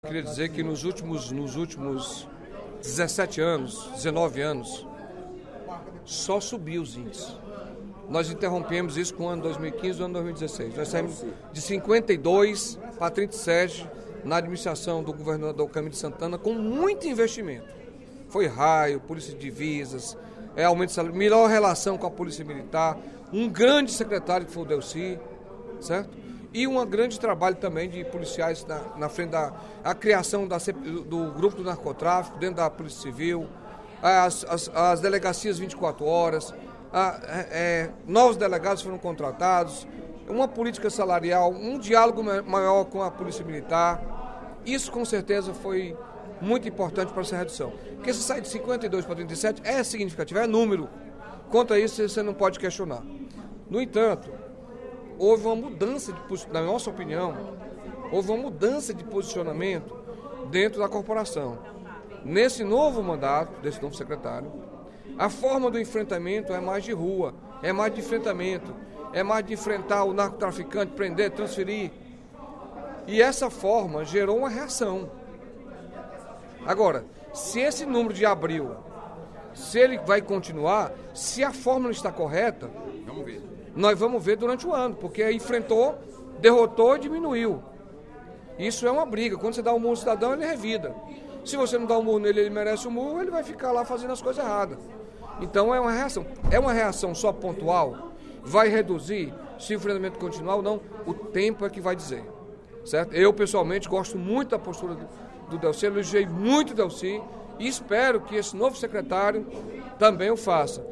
O deputado Carlos Felipe (PCdoB) salientou, durante o primeiro expediente da sessão plenária desta quinta-feira (11/05), os investimentos do Governo do Estado para combater a violência.